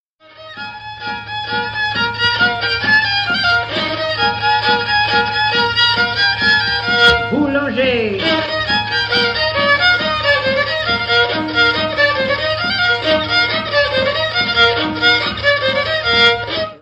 Mémoires et Patrimoines vivants - RaddO est une base de données d'archives iconographiques et sonores.
danse : ronde : boulangère
Pièce musicale inédite